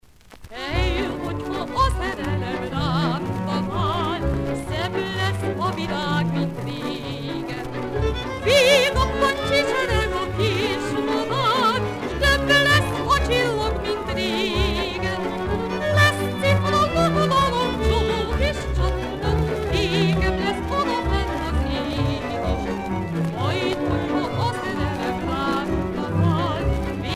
danse : csárdás (Hongrie)